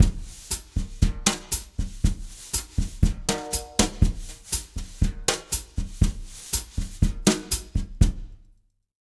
120BOSSA03-R.wav